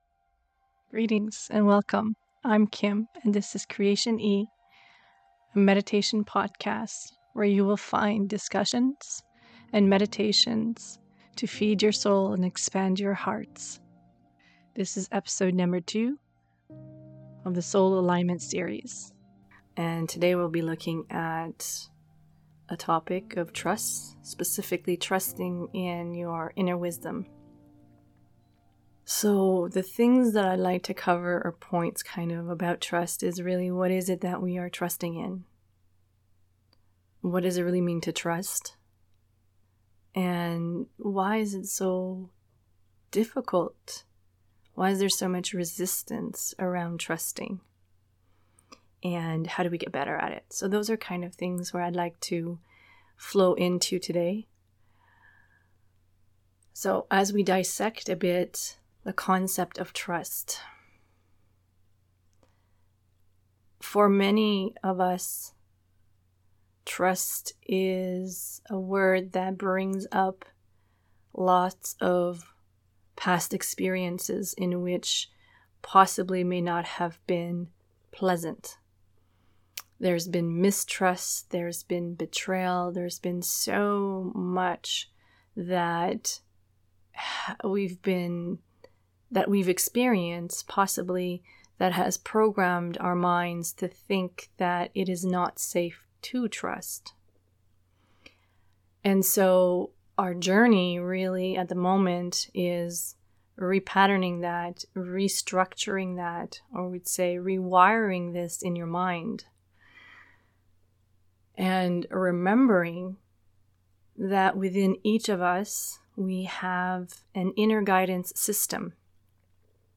Key topics in this conversation include: Intuition, inner guidance system, overriding the mental control mechanism and much more The guided meditation following the message leads you into a deeper state of inner presence.